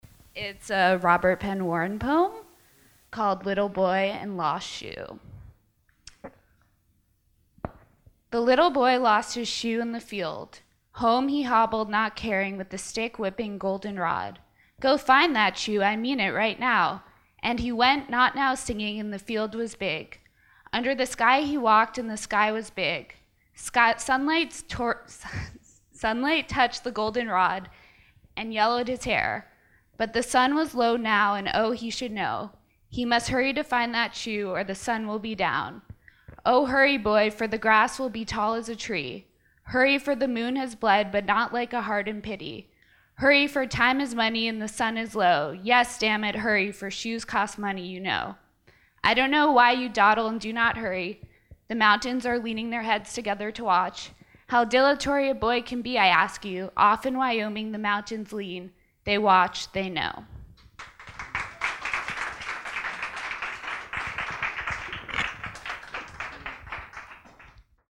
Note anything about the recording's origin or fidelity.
My Hero Poetry salon 2015: Little Boy and Lost Shoe